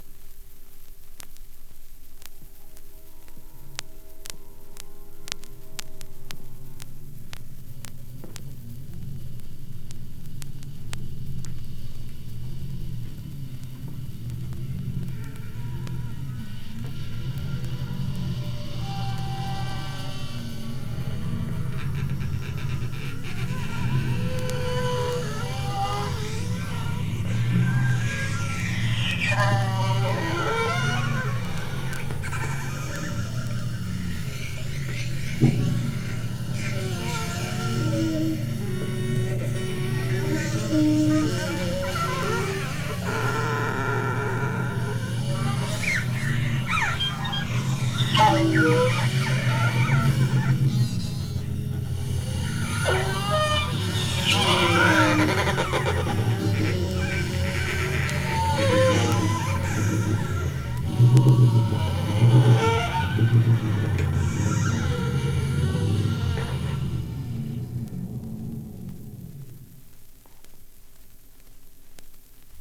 Vinyl digitalisiert mit:
1A1 01 bow on bow sextet, turn table (16 Kanal stereo) 17.03